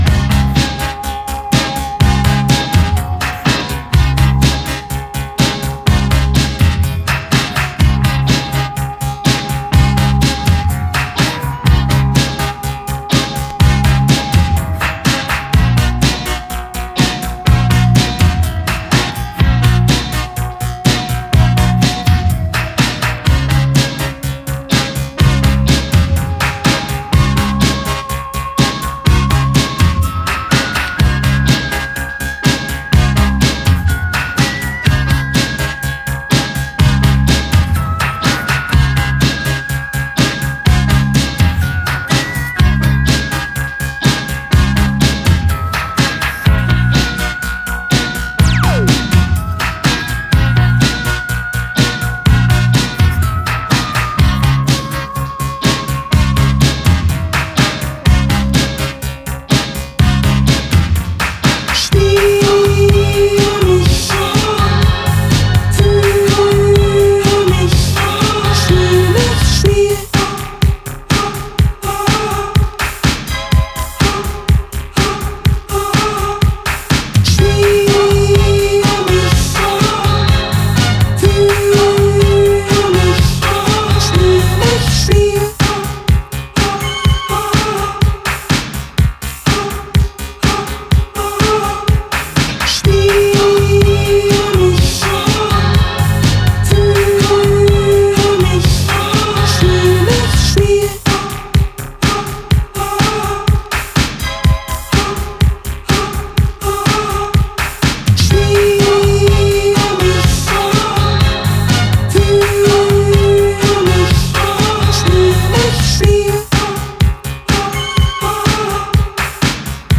Four groovers of Cosmic, Disco and Italo adventures for all.